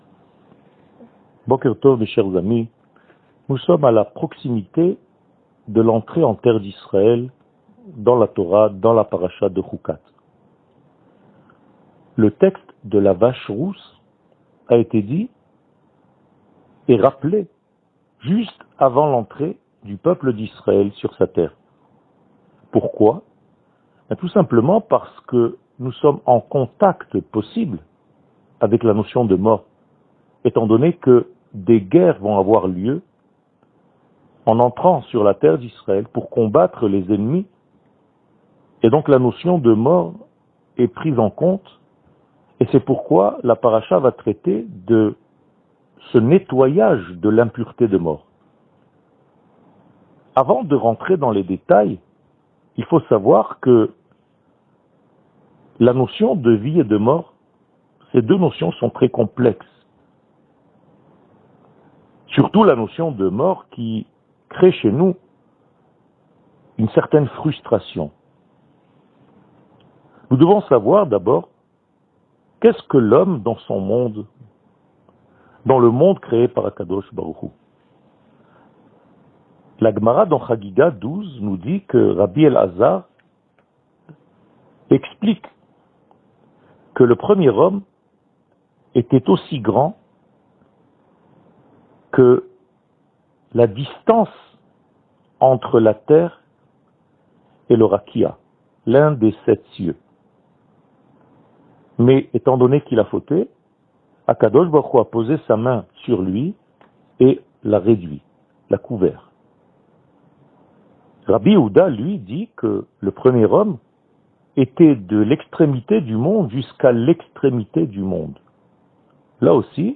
שיעורים קצרים